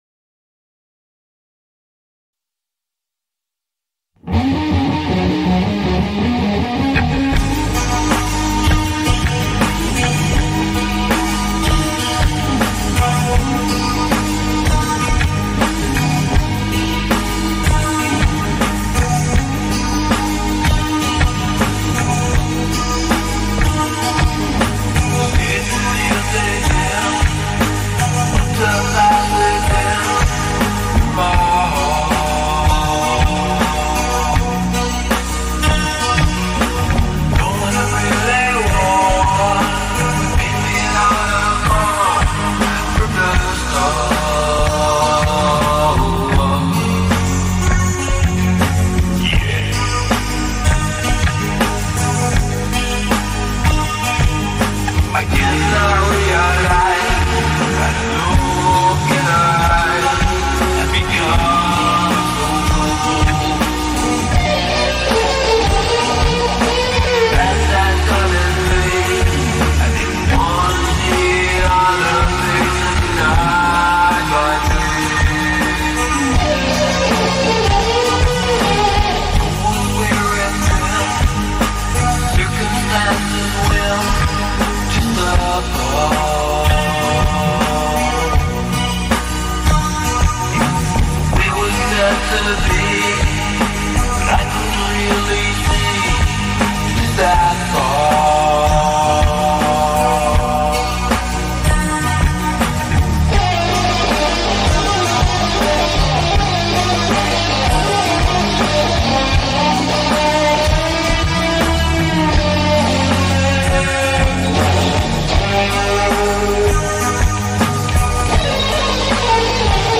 Christian Classic Rock & Instrumental Guitar Hymns